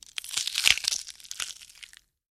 Звук движения мумии тела